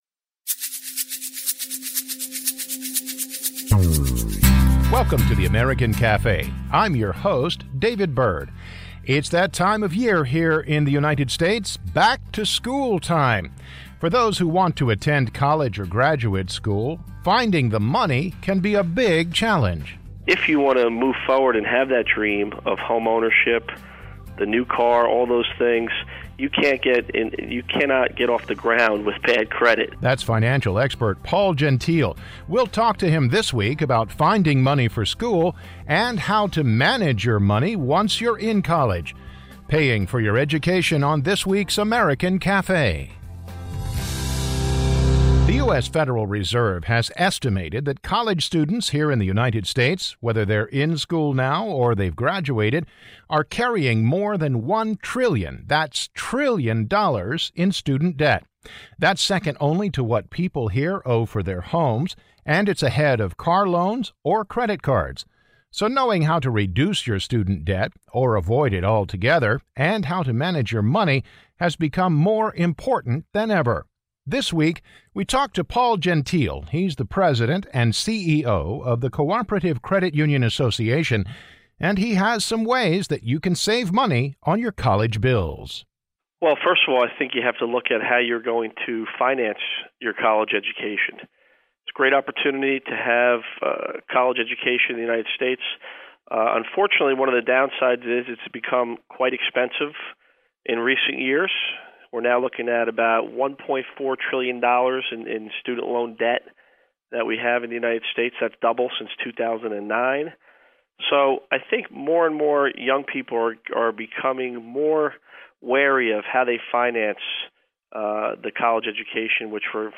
American college debt is more than $1.4 trillion. This week American Café talks to a financial expert about how to save money on college and how to avoid getting in crippling debt.